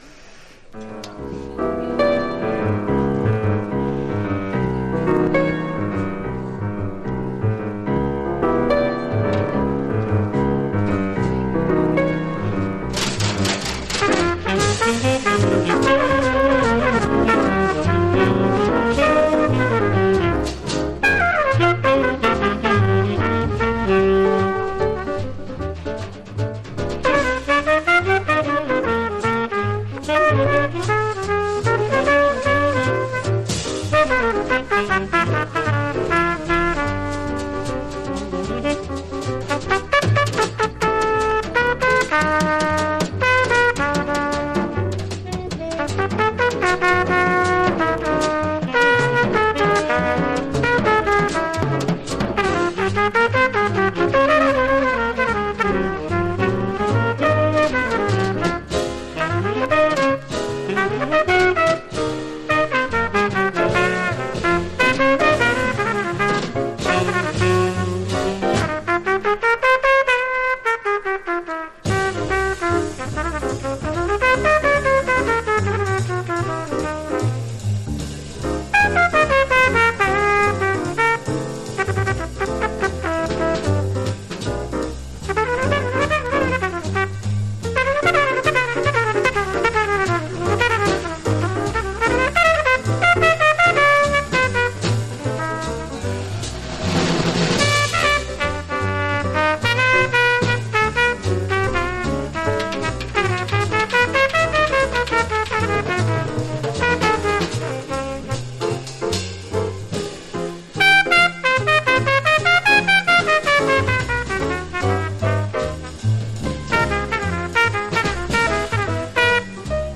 Genre US JAZZ